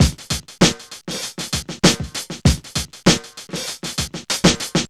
FUNKDRUM 98.wav